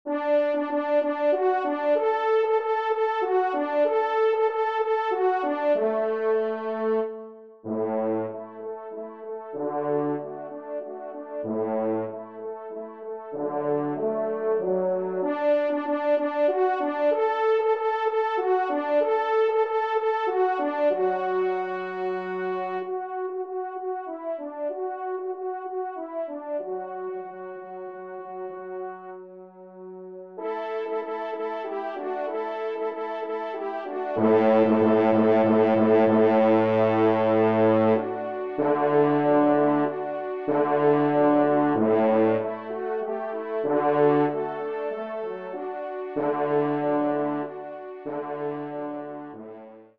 Genre :  Divertissement pour Trompes ou Cors en Ré
5e Trompe